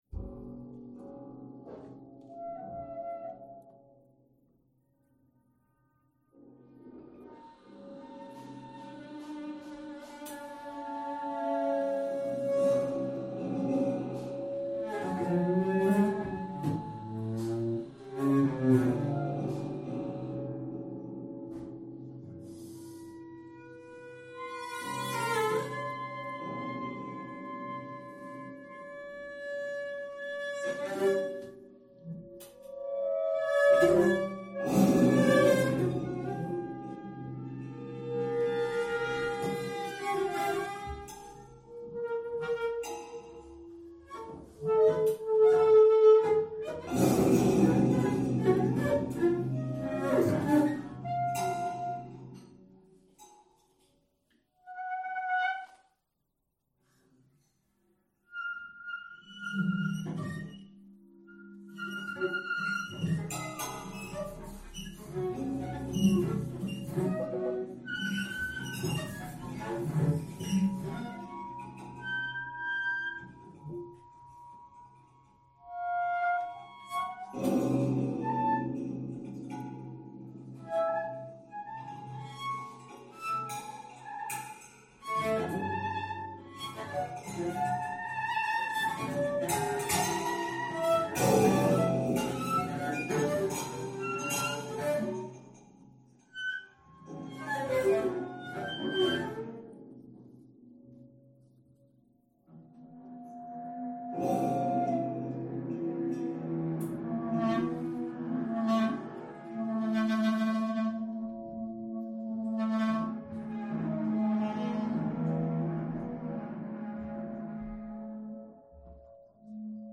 Electronics and sound objects
Clarinet